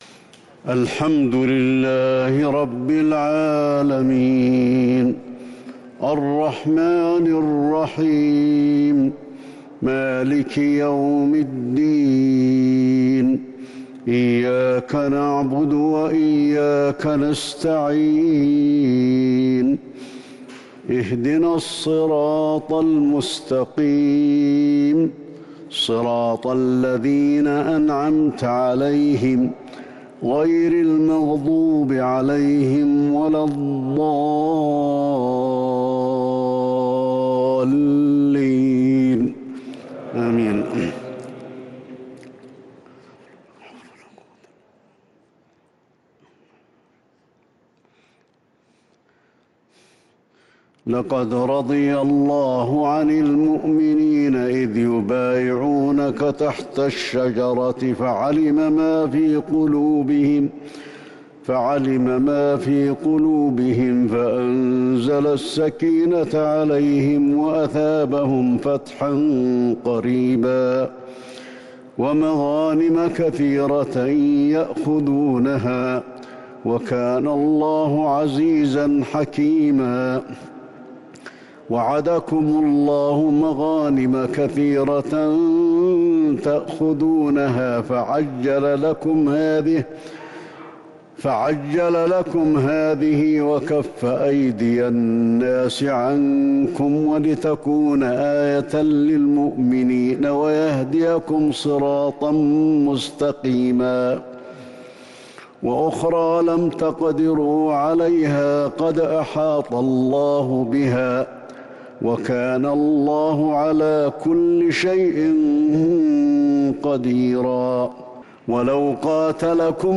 صلاة التهجّد من سورتيّ الفتح والحجرات ليلة 27 رمضان 1442 | Prayer prayer from Surat Al-Fath and Al-Hujrah on the night of Ramadan 27, 1442 > تراويح الحرم النبوي عام 1442 🕌 > التراويح - تلاوات الحرمين